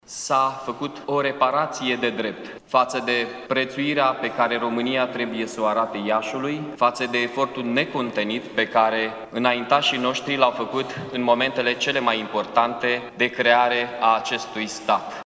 La Palatul Culturii, în prezenţa preşedintelui Klaus Iohannis, a avut loc, în această seară, ceremonia de înmânare a unei copii a Decretului de promulgare a Legii prin care municipiul Iaşi este declarat capitală istorică.
Primarul Mihai Chirica despre importanţa titlului de Capitală Istorică a României, conferit muncipiului Iaşi:
23-ian-rdj-20-M-Chirica-discurs.mp3